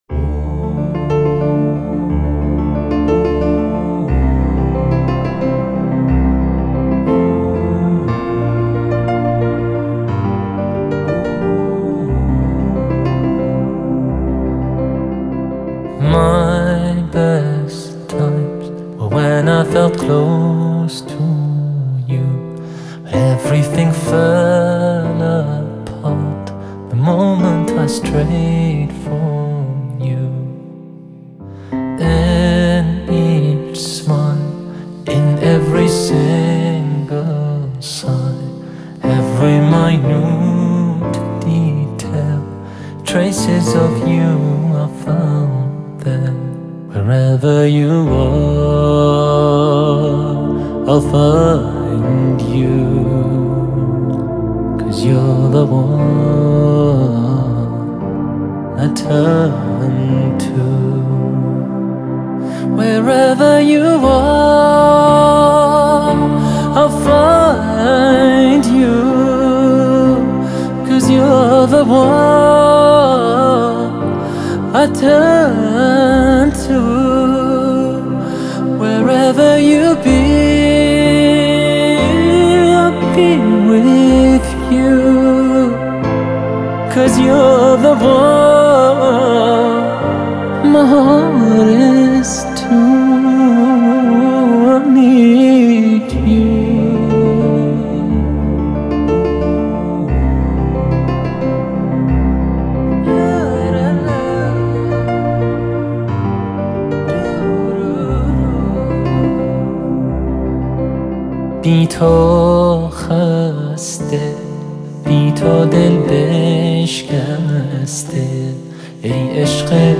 اناشيد و اغاني اسلامية